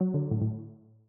call_timeout-DPKAj6sz.ogg